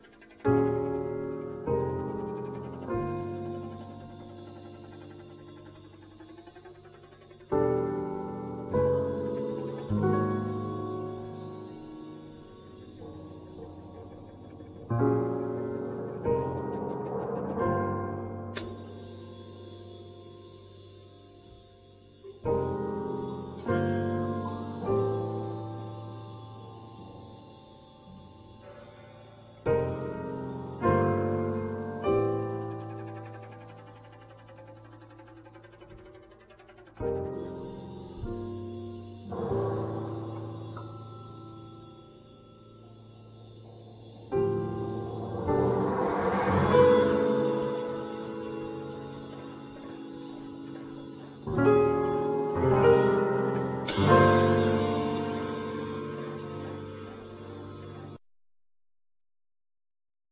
Piano, Percussion, Prepared piano
Drums, Gong